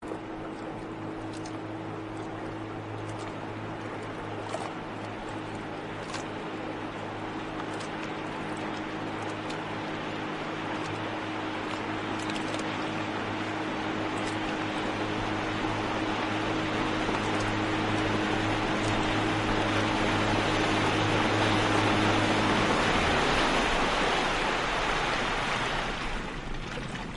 SFX船外游艇开船声音效下载